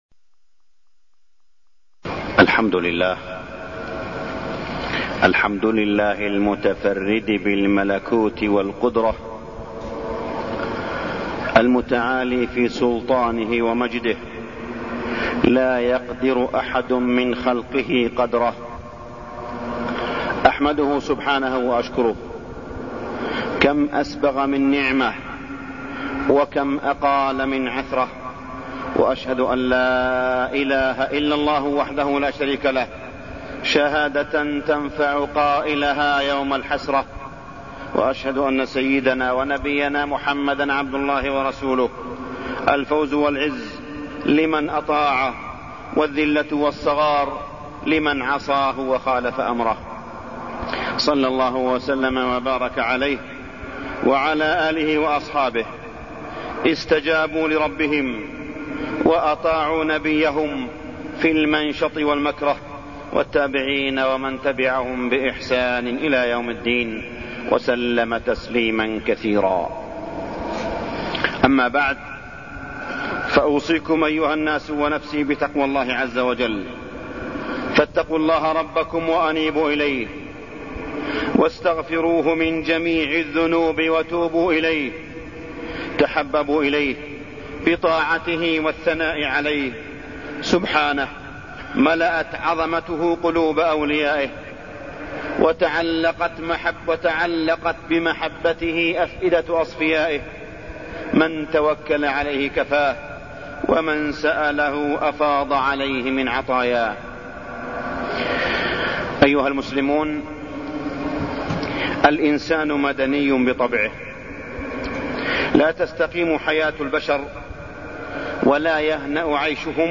تاريخ النشر ١٤ شوال ١٤٢٠ هـ المكان: المسجد الحرام الشيخ: معالي الشيخ أ.د. صالح بن عبدالله بن حميد معالي الشيخ أ.د. صالح بن عبدالله بن حميد الدعوة والإرشاد